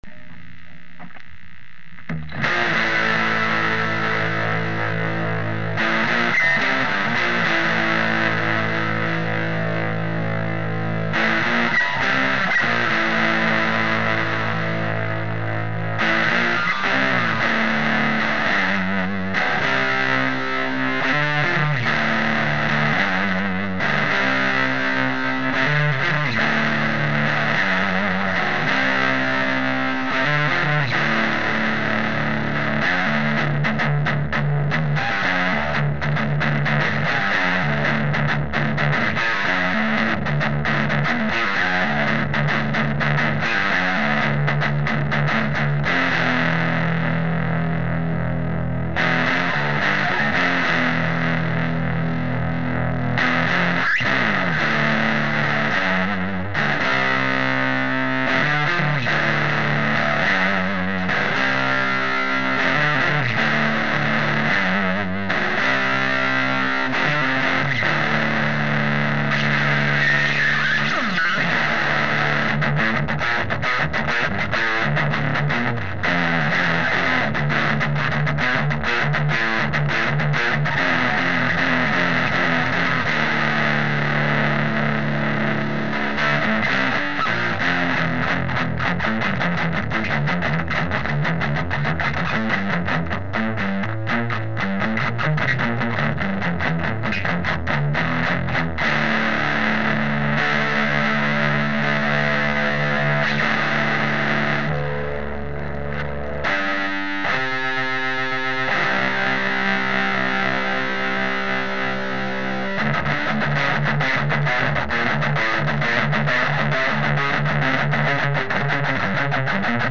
No effects were used either in the recording or mix down.